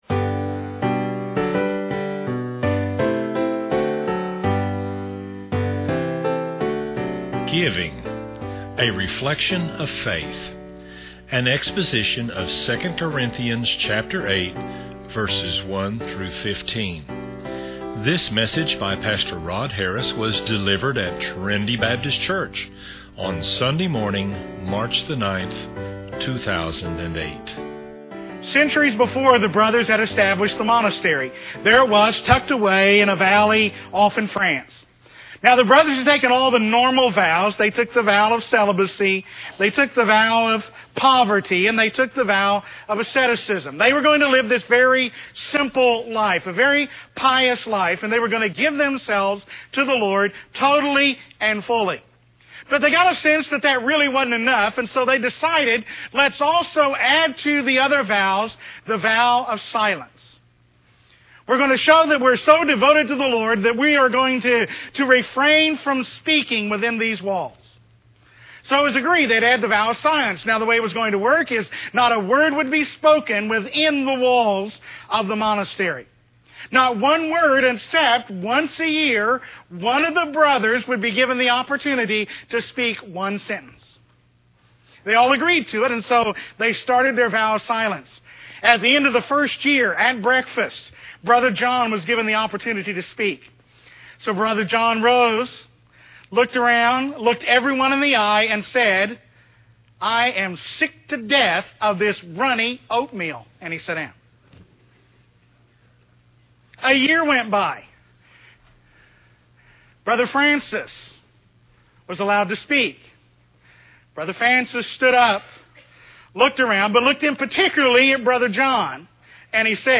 Sermons on Stewardship - TBCTulsa